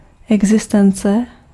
Ääntäminen
UK : IPA : /ˈbiː.ɪŋ/ US : IPA : [ˈbiː.ɪŋ] US : IPA : /ˈbiɪŋ/